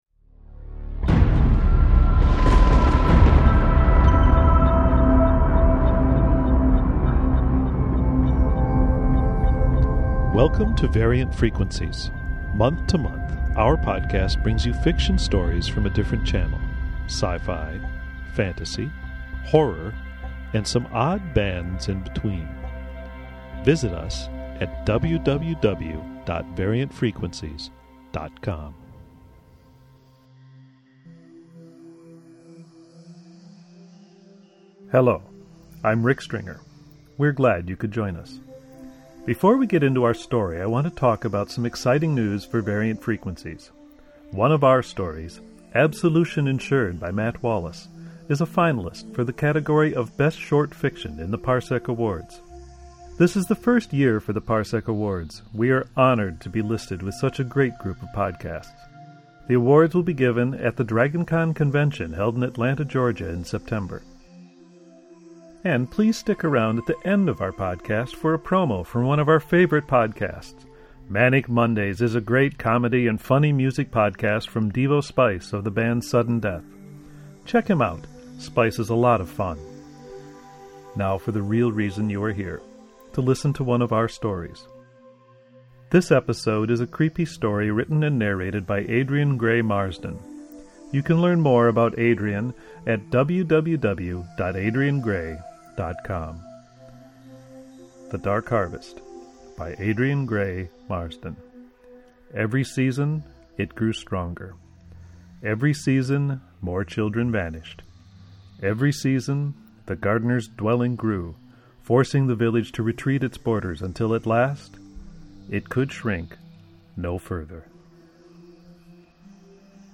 Audio Fiction